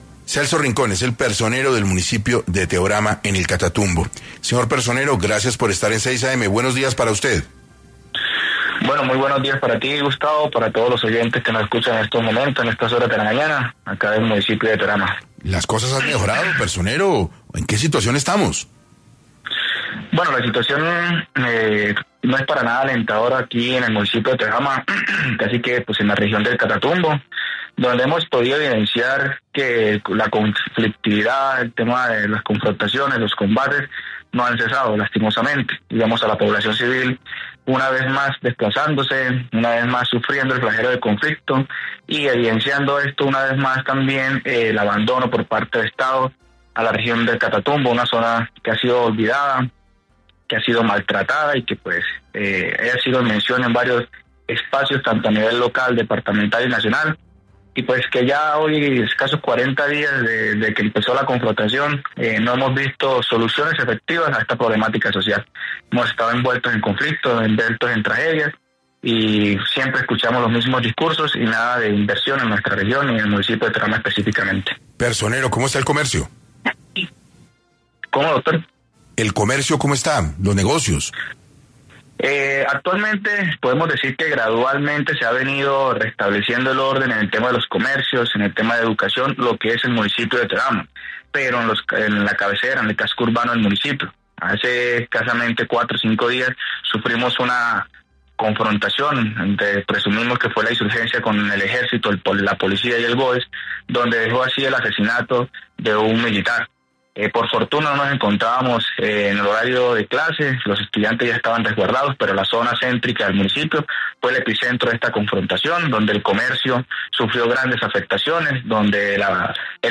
En entrevista para 6AM, el personero del municipio de Teorama en el Catatumbo, Celso Rincón, expuso el panorama actual del conflicto en la zona, y explicó las necesidades de la región.